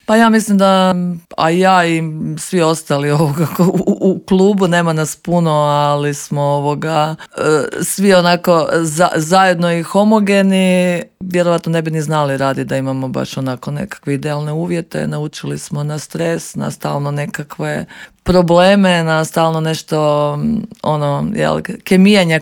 O pripremama za ove dvije dvije važne utakmice sa švicarskim Amicitom iz Zuricha govorila je u Intervjuu Media servisa direktorica kluba i naša legendarna bivša rukometašica Klaudija Bubalo (Klikovac).